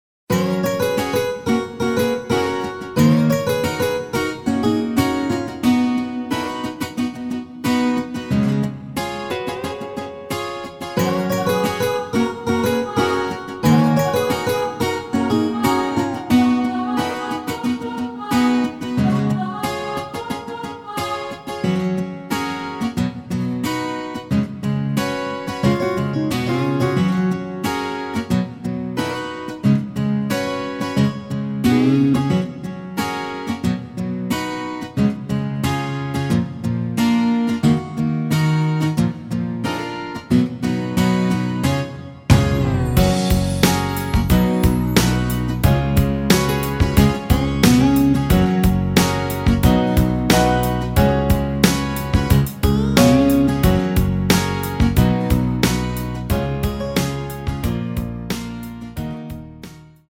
원키에서(+5)올린 MR입니다.
원곡의 보컬 목소리를 MR에 약하게 넣어서 제작한 MR이며